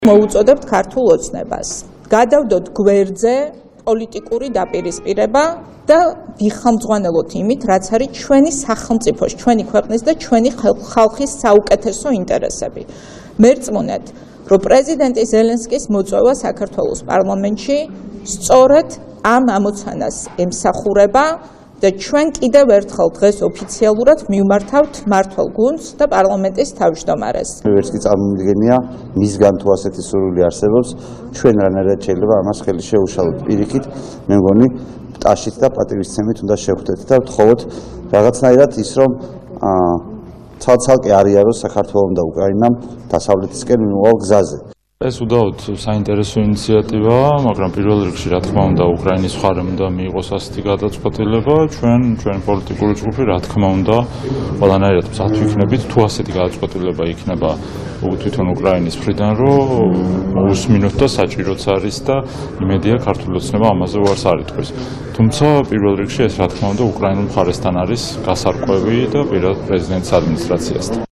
სალომე სამადაშვილის,იაგო ხვიჩიას და სერგო ჩიხლაძის ხმა